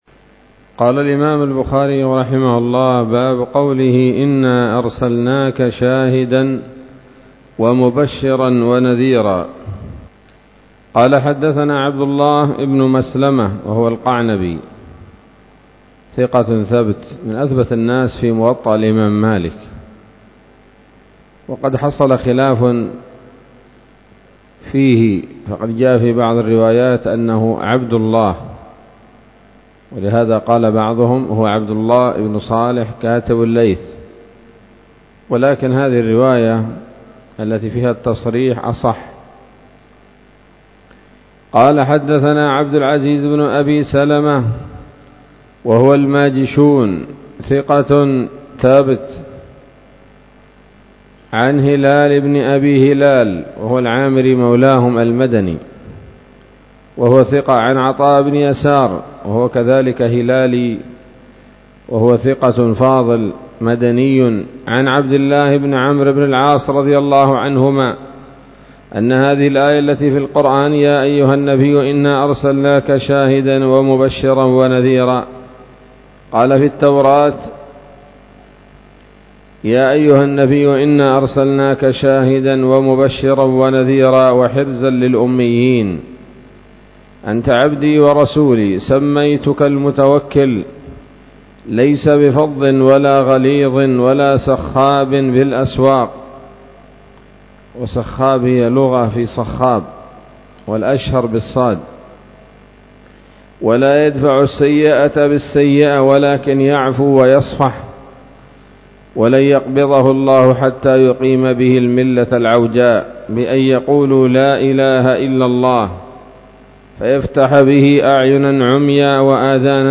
الدرس الثالث والثلاثون بعد المائتين من كتاب التفسير من صحيح الإمام البخاري